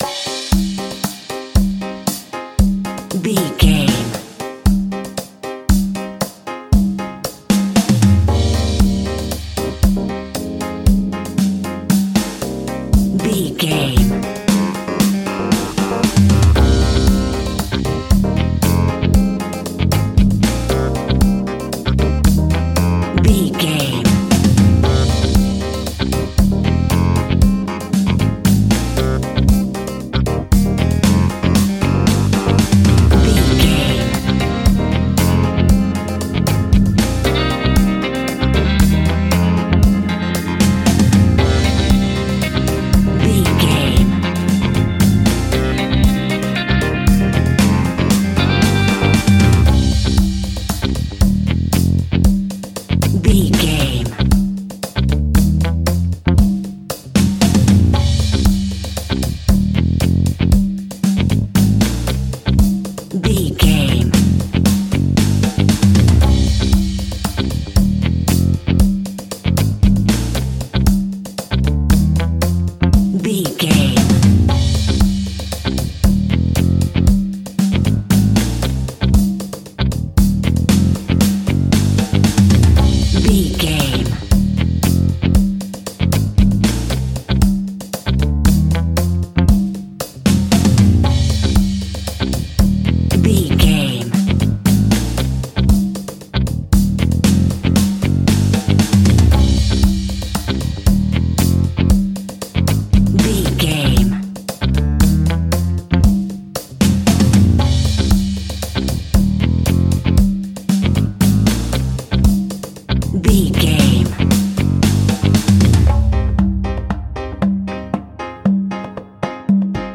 Aeolian/Minor
dub
instrumentals
laid back
chilled
off beat
drums
skank guitar
hammond organ
transistor guitar
percussion
horns